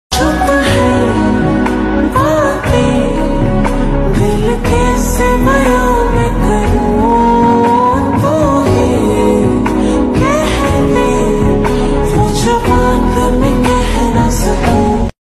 Happy Mood Song